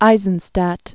(īzən-stăt), Alfred 1898-1995.